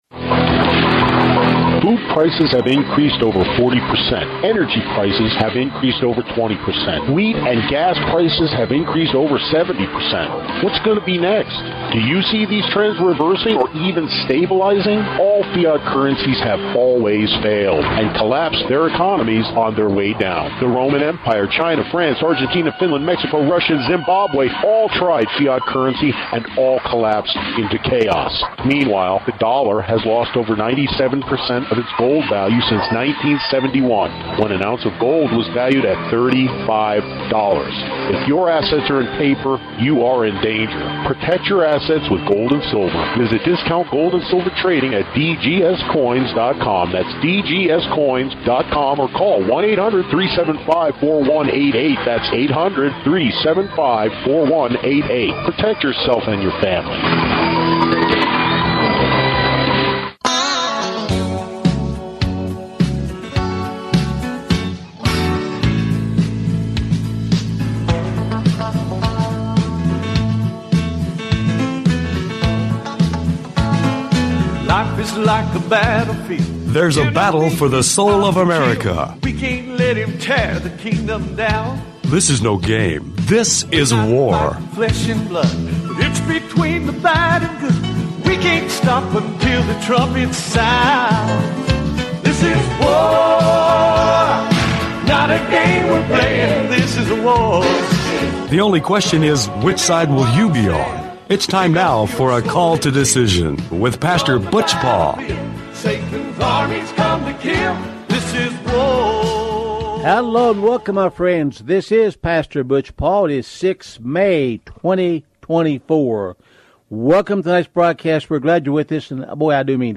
Call To Decision Talk Show